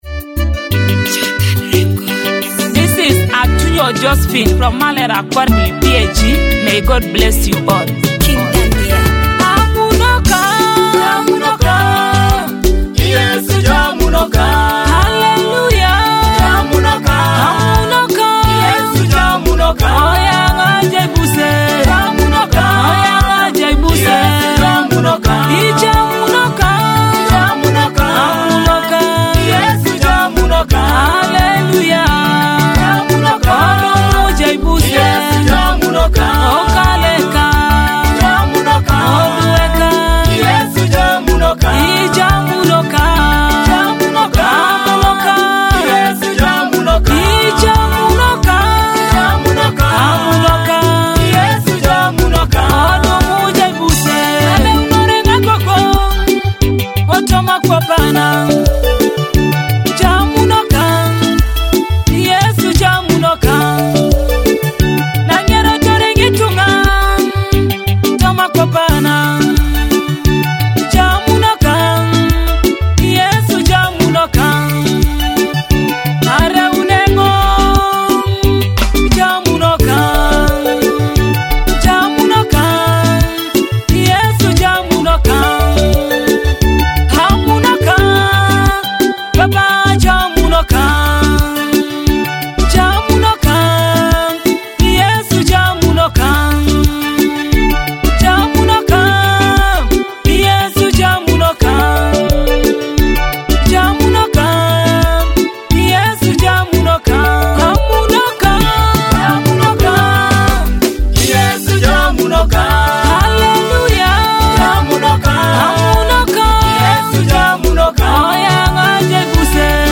Soul-stirring worship track